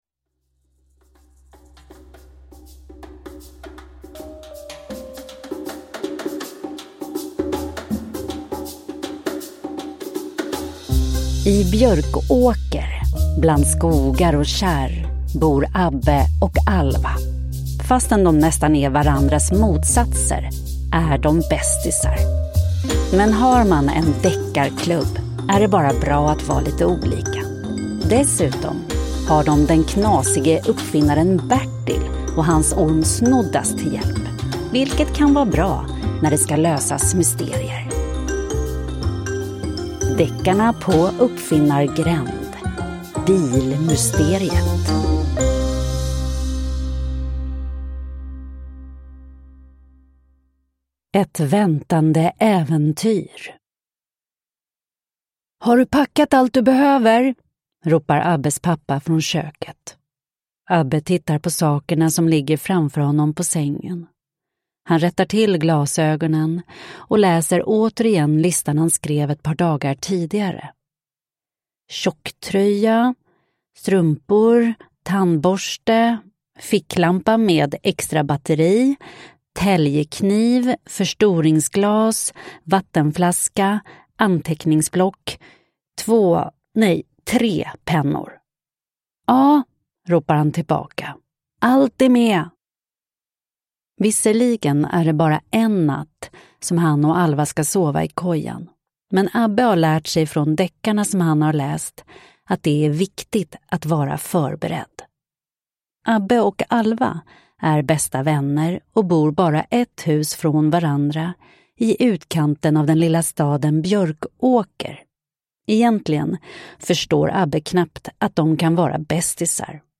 Bilmysteriet – Ljudbok